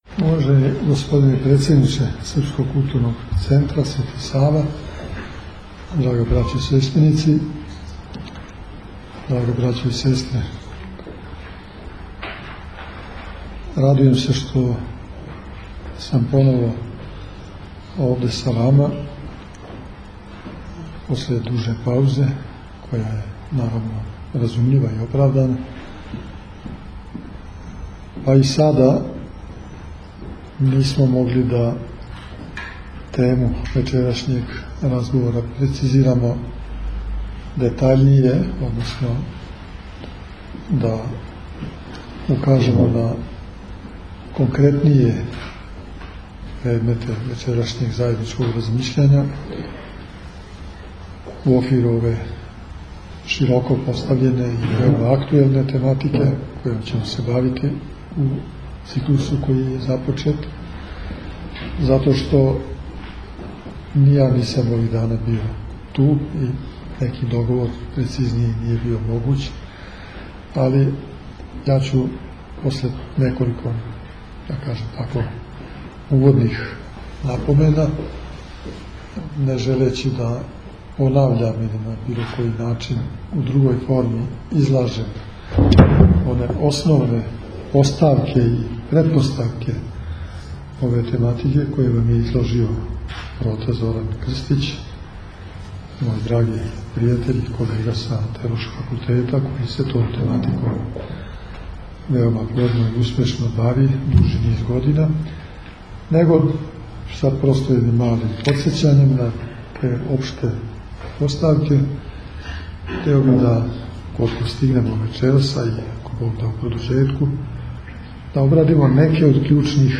Епископ Иринеј одржао предавање у Суботици
Пред многобројним слушаоцима сабраним у свечаној дворани Српског културног центра Свети Сава у Суботици, у четвртак 27. октобра 2011. године, Његово Преосвештенство Епископ бачки Господин др Иринеј одржао је предавање на тему Социолошки и етички изазови пред хришћанском свешћу данас.